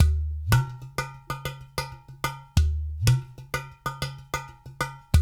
93 -UDU 07R.wav